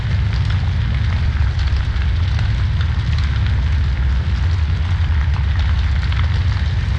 FireMedium.ogg